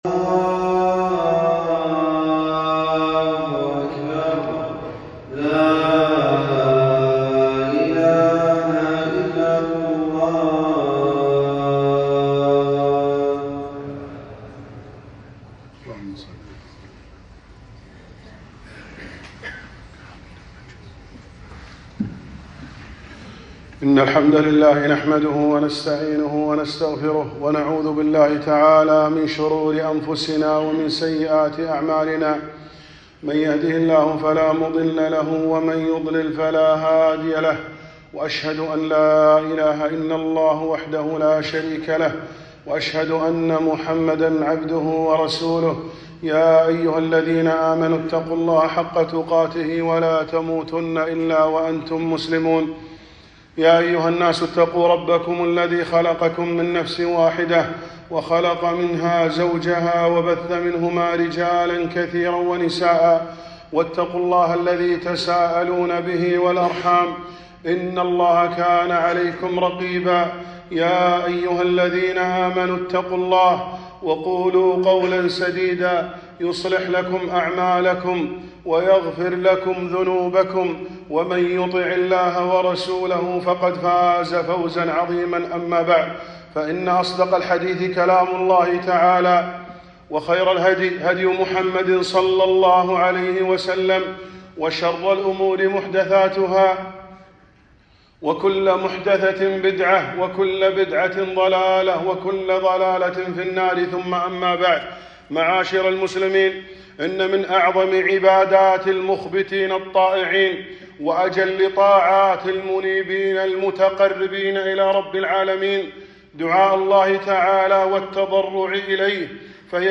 خطبة - الدعاء سلاح المؤمن